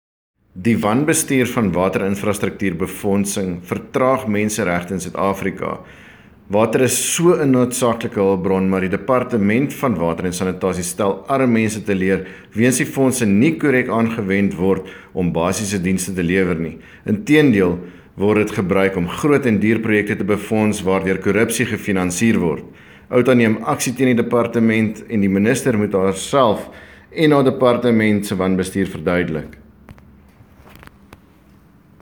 Afrikaans sound bite